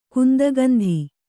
♪ kundagandhi